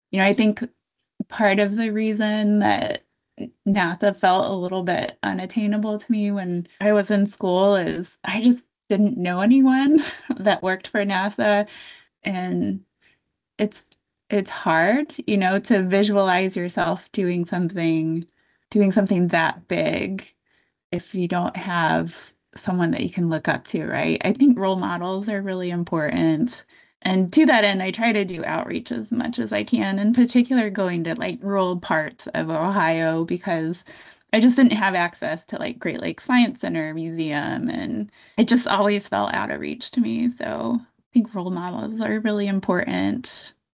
artemis-intv-bite.wav